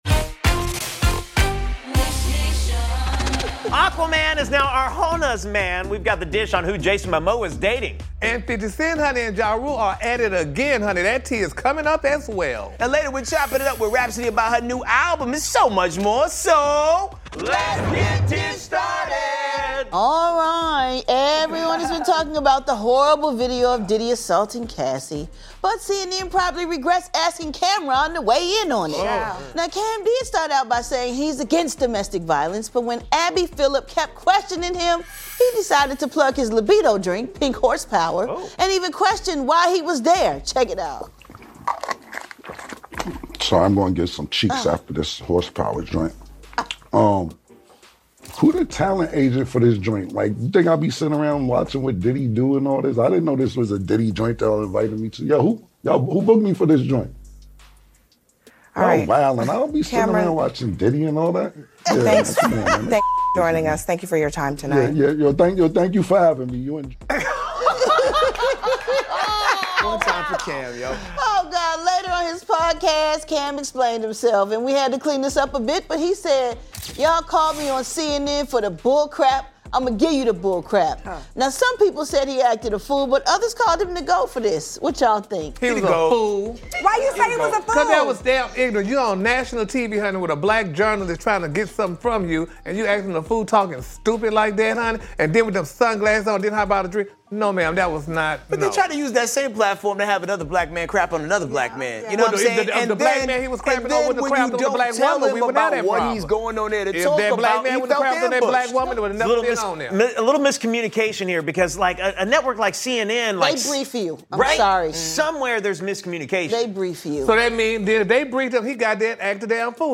Plus, Jason Momoa goes "official" with his new girlfriend, and we dish with Grammy award-winning rapper Rapsody about her new album 'Please Don't Cry,' the Kendrick Lamar and Drake beef, and more on today's Dish Nation!